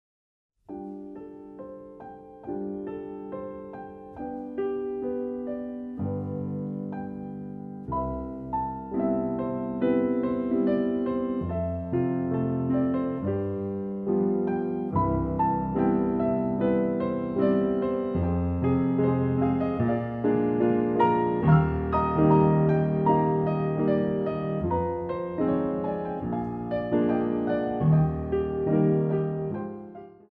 Young dancers Ballet Class
The CD is beautifully recorded on a Steinway piano.
Exercise préparatoire